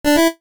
jingles-retro_00.ogg